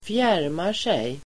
Uttal: [²fj'är:mar_sej]